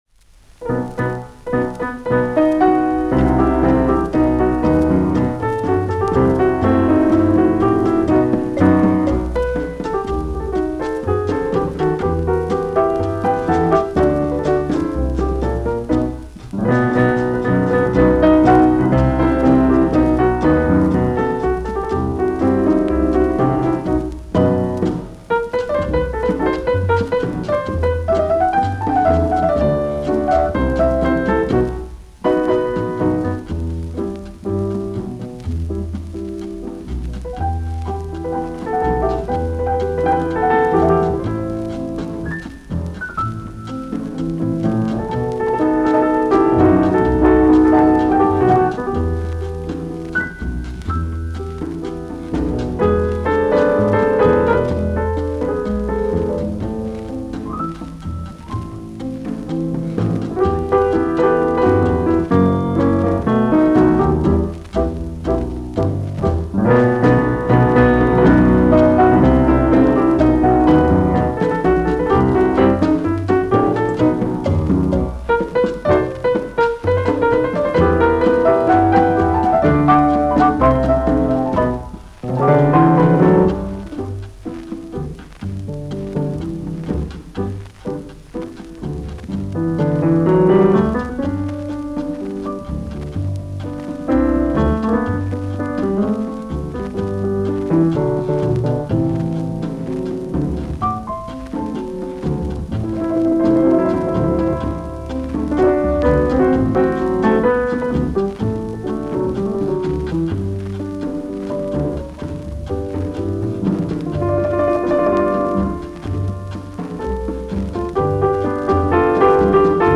Originally issued on a 78 rpm disc in the 1950s
solo piano